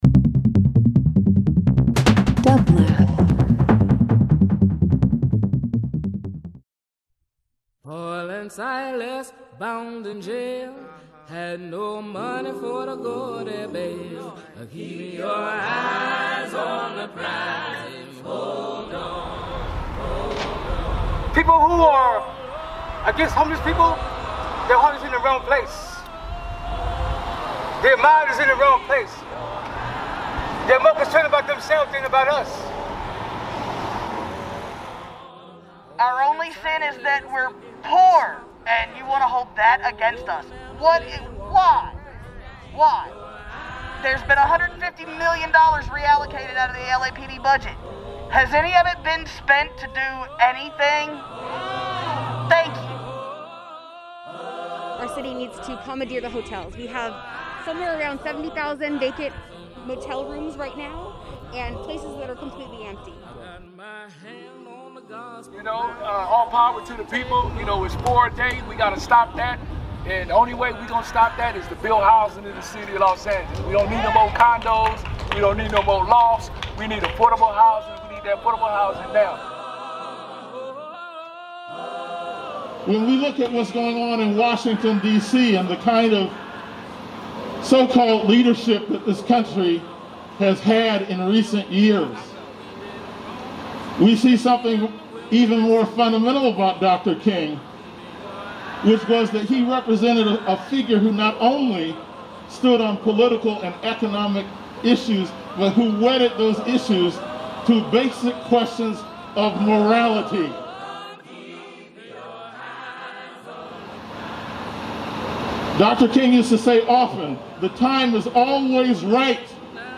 Justice for the Unhoused community is ending criminalizing them with brutal sweeps. Please hear their first hand accounts and the organizers who are fighting with them.
Field Recording Interview Talk Show